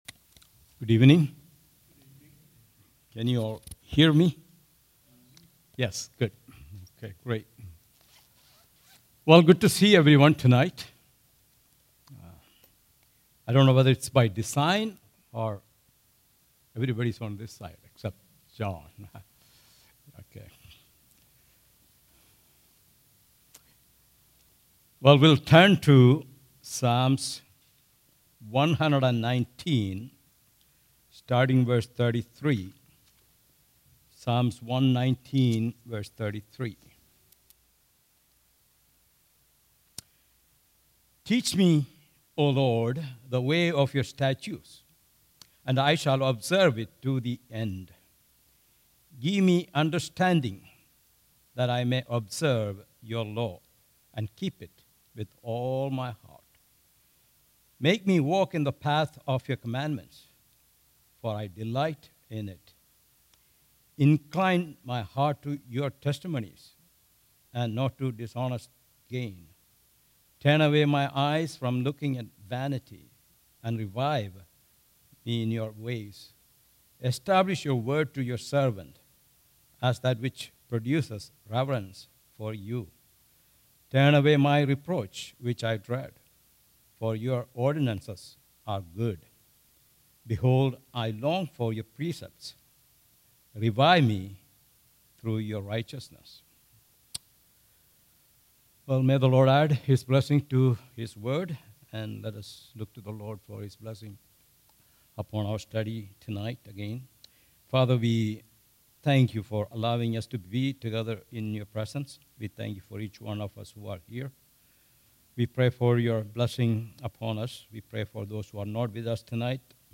All Sermons Psalm 119:33-40